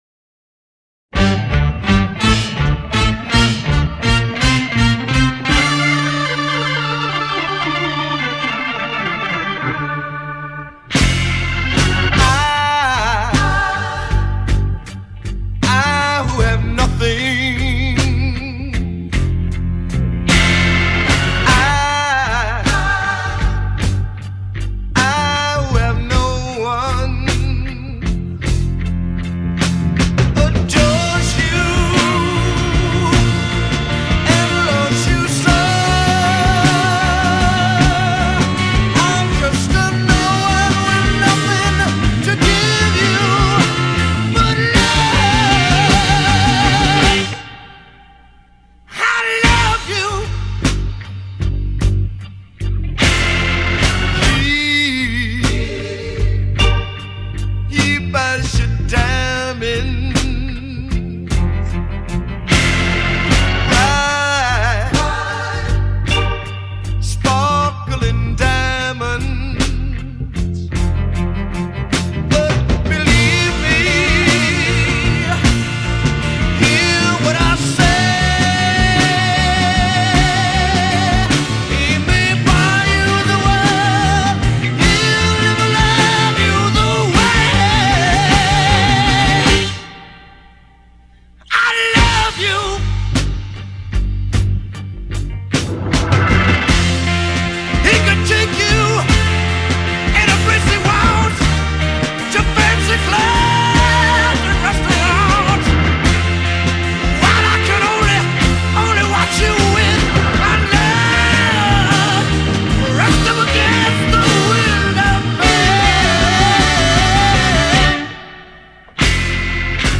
Как же я обожаю звучание живых эфирных записей))) Забрал ее.